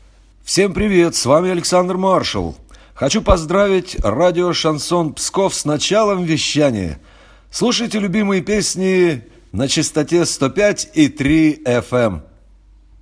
Певец Александр Маршал поздравил радио «Шансон» с началом вещания в Пскове.